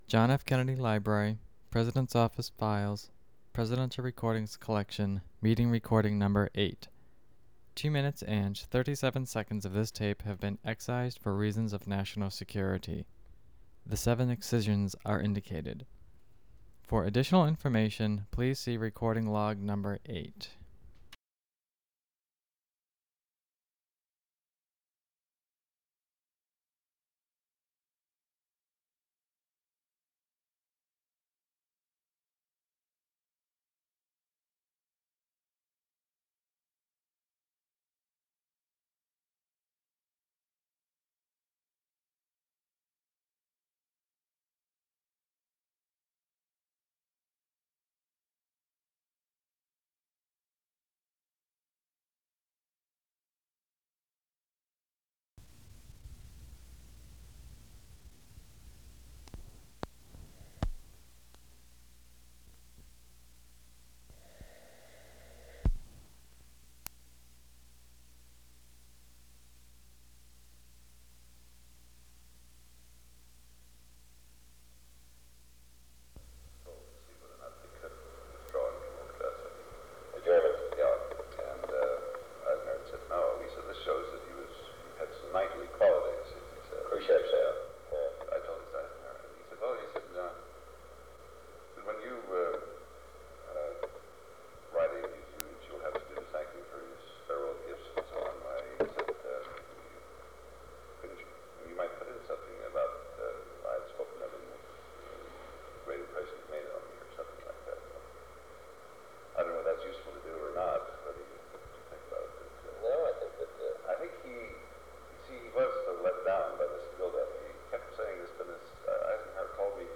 Secret White House Tapes | John F. Kennedy Presidency Meeting on Berlin Rewind 10 seconds Play/Pause Fast-forward 10 seconds 0:00 Download audio Previous Meetings: Tape 121/A57.